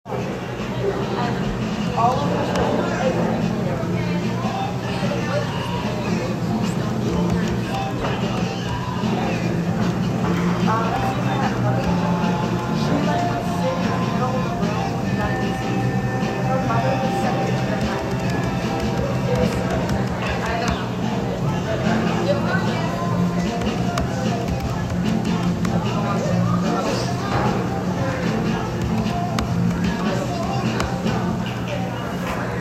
Field Recording
Location: Student Center on Friday
Sounds: Chattering, music playing from radio, clicking sounds from my keyboard